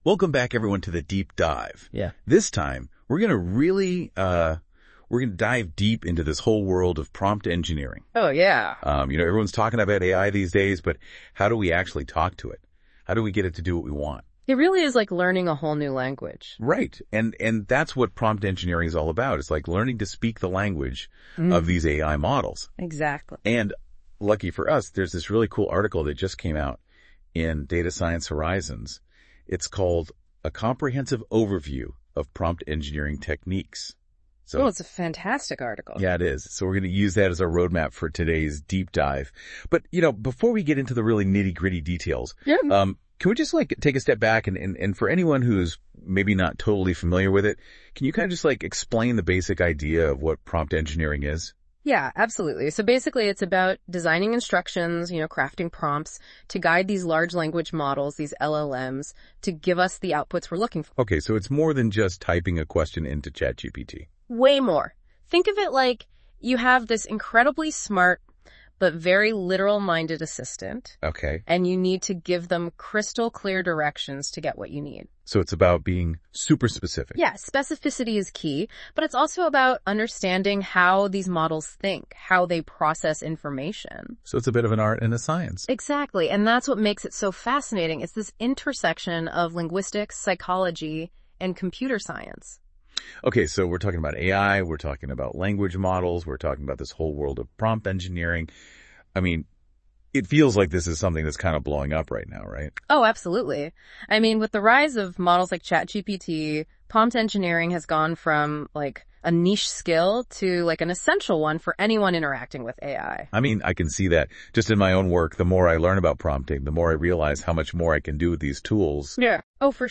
Listen to a NotebookLM-generated conversation of this article’s content • Download